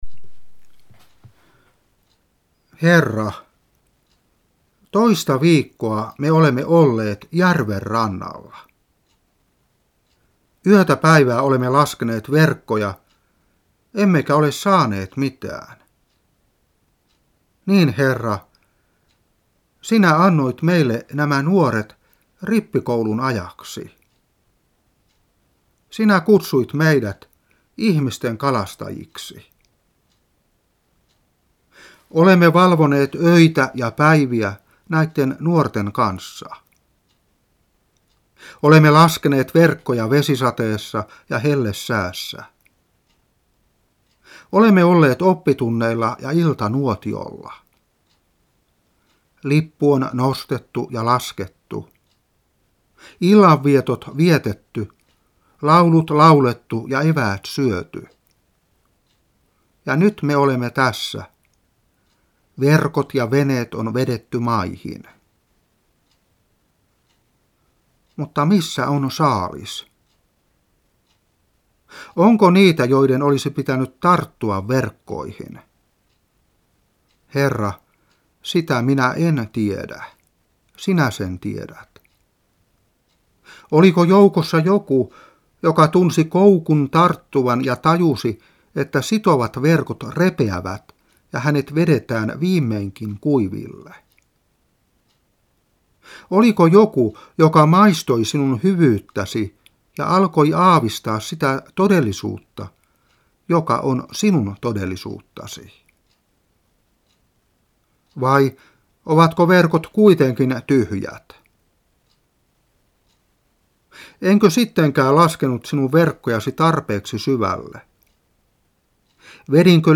Saarna 2001-7.